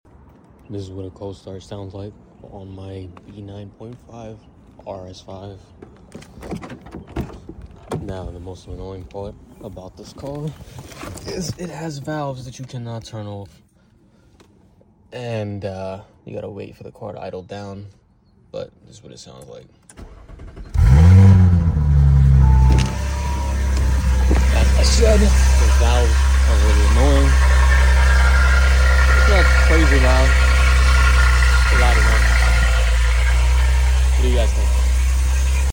Cold start on my b9.5 sound effects free download
Cold start on my b9.5 rs5 with downpipes and a resonator delete